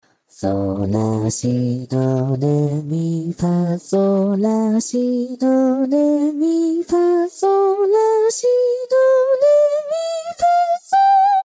藤咲透明_JPVCV_無気力（whisper）推薦     DL
收錄音階：G3